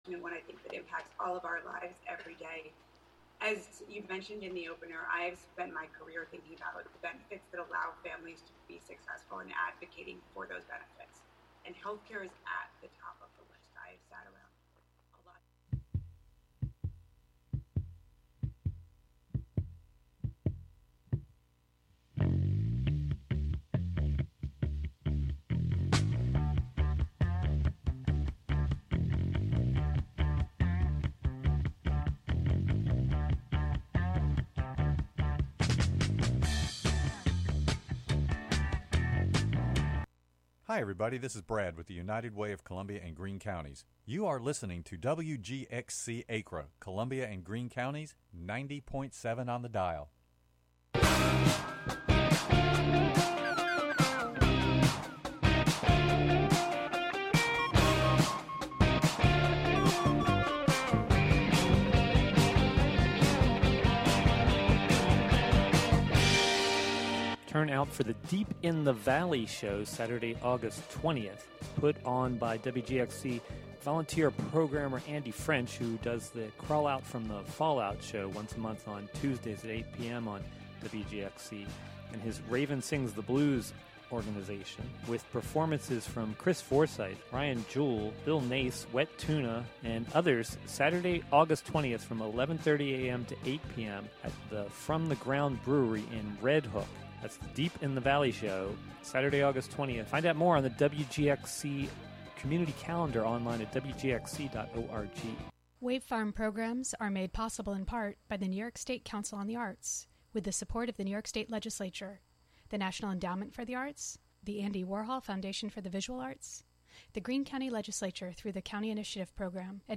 The band recently gathered in Palenville to chat over Zoom about the making of this record, collaboration, friendship, the mystery of existence, carrot cake, and more.
The show features music, field recordings, performances, and interviews, primarily with people in and around the Catskill Mountains of New York live from WGXC's Acra studio.